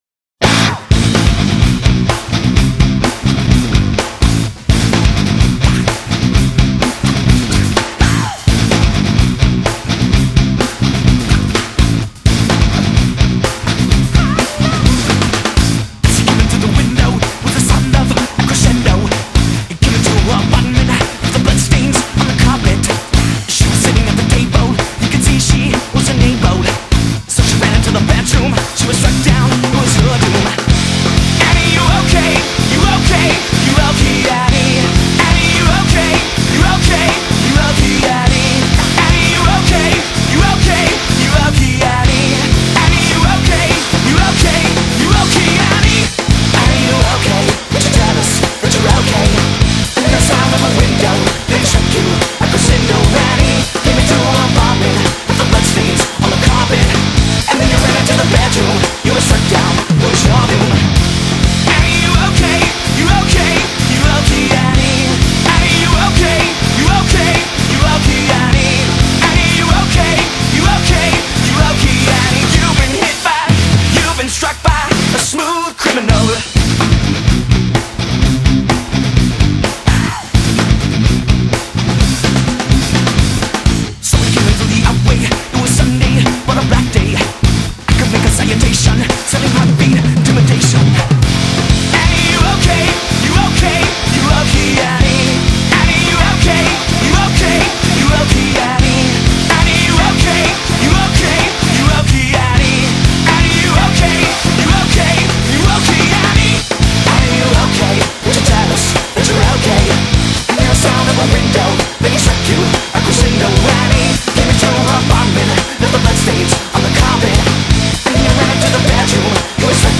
2024-08-22 19:59:15 Gênero: Rock Views